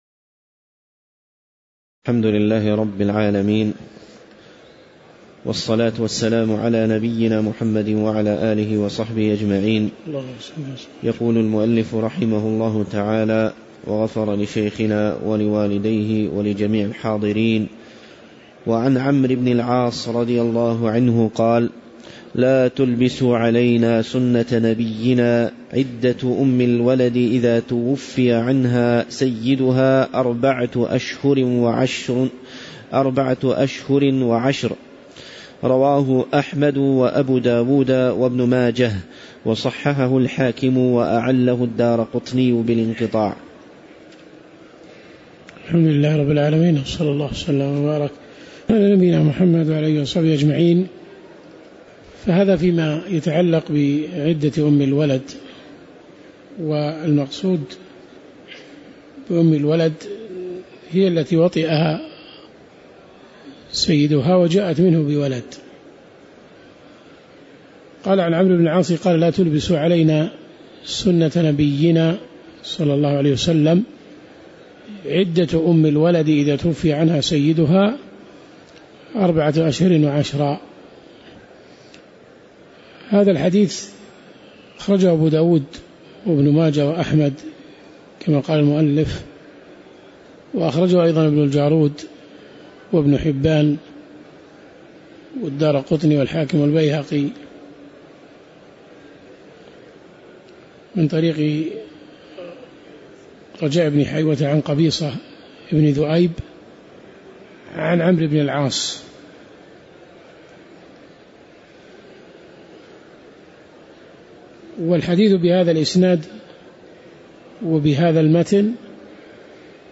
تاريخ النشر ٤ صفر ١٤٣٩ هـ المكان: المسجد النبوي الشيخ